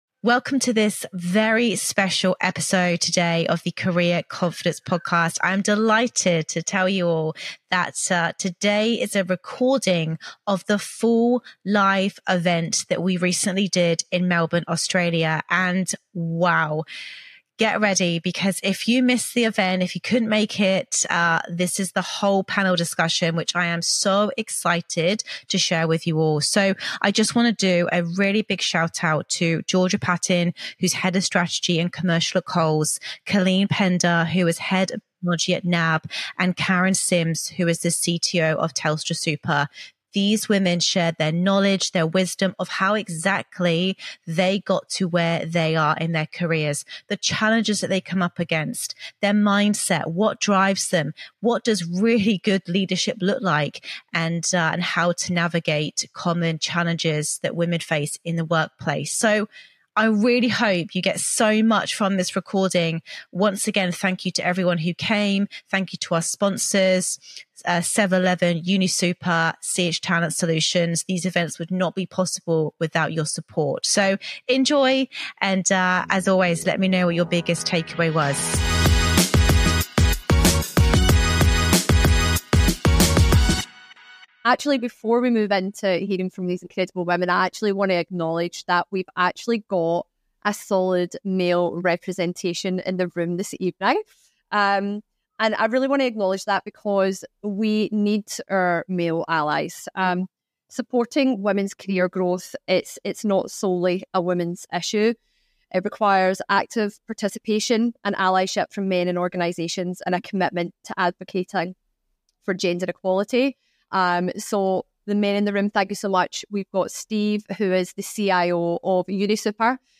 In this special episode of the LeadHERship Labs podcast, recorded live at the Sisterhood Club event in Melbourne, we bring together a powerhouse panel of female leaders in technology to discuss their career journeys, leadership styles, and the challenges they’ve faced in a male-dominated industry.